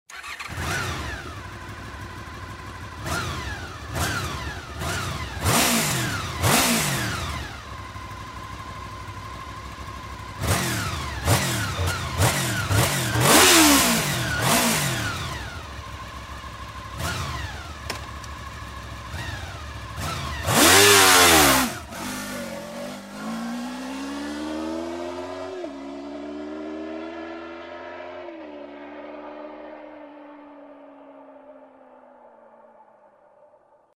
Lyssna på VFR800 med originaldämpare
Lugn motorgång och bra vridmoment gör den till en tourerfavorit.